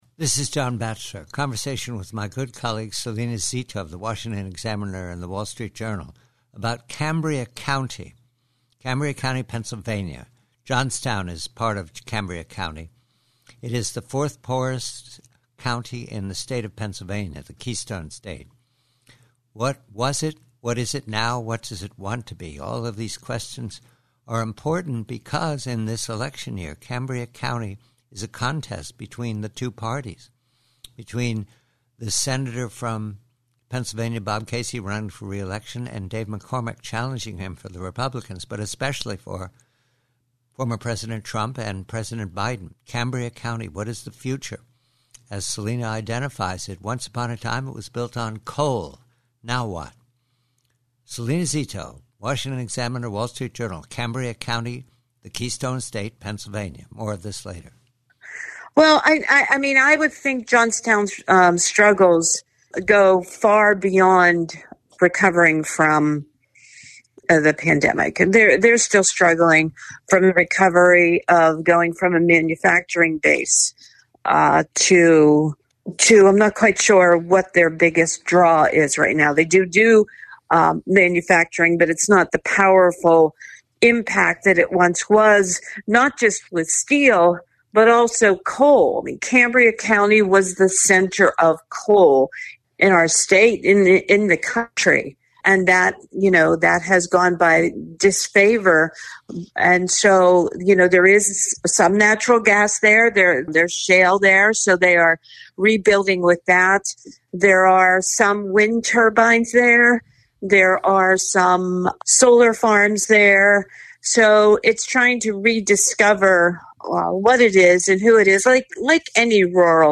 PREVIEW:CAMBRIA COUNTY: Conversation with colleague Salena Zito re Cambria County, PA, once the coal center of the state, now the fourth poorest county in Pennsylvania, and what it seeks to reawaken itself -- What must be done.